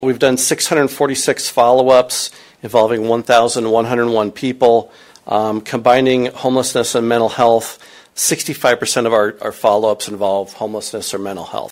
MPACT Quarterly Report at Marshalltown City Council Meeting | News-Talk 1230 KFJB